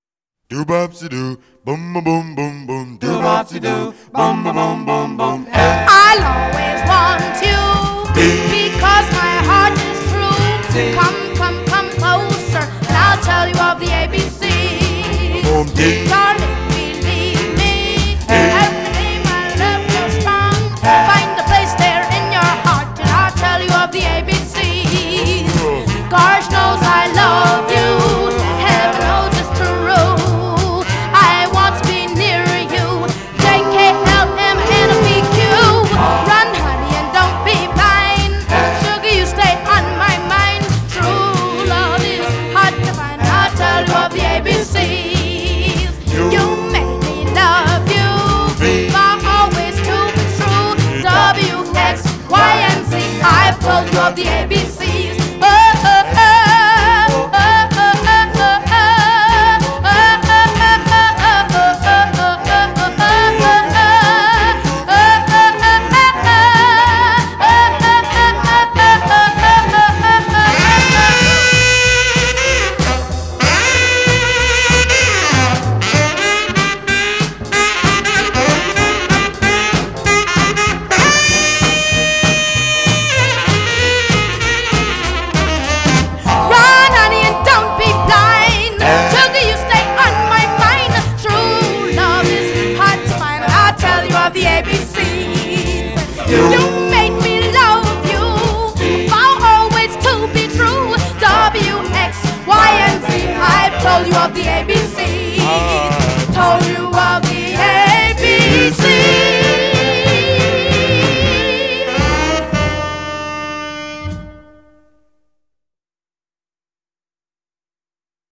early Rock 'N Roll